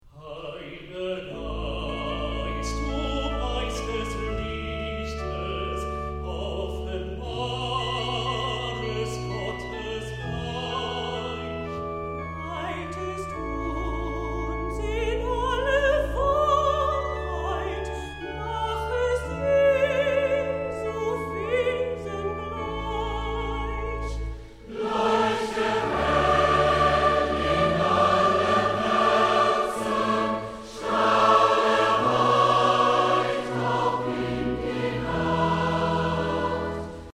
Soli, Chor, Klavier, Orgel